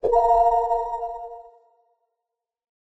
heal.ogg